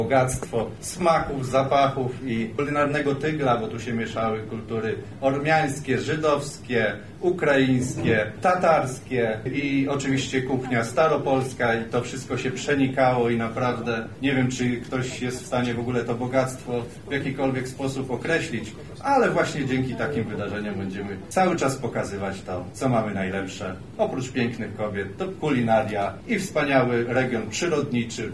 wojciechowski – mówi Marek Wojciechowski, Wicemarszałek Województwa Lubelskiego.